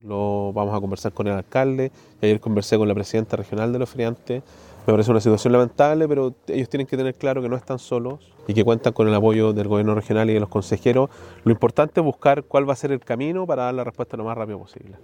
Respecto a la ayuda, el gobernador Sergio Giacaman indicó que se trabaja para coordinar la entrega de la ayuda junto al Sindicato de Ferias Libres, el alcalde de Lota, Jaime Vásquez, y autoridades sectoriales.